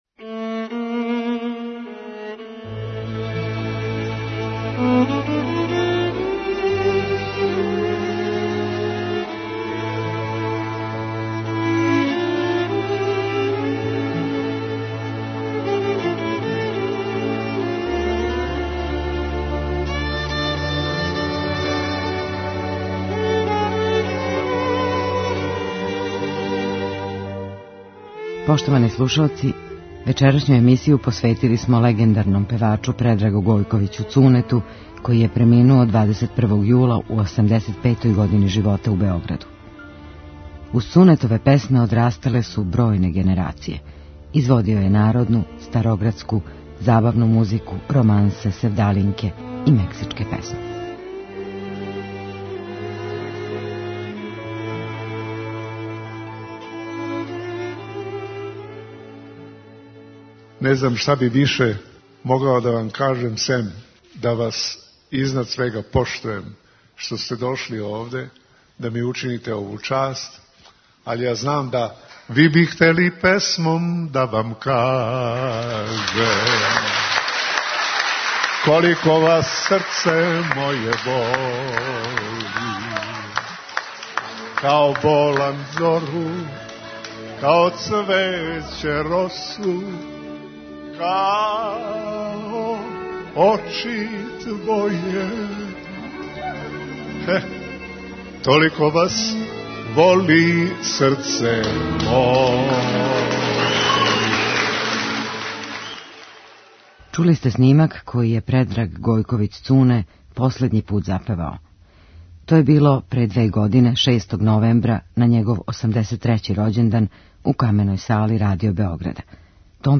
староградских песама и романси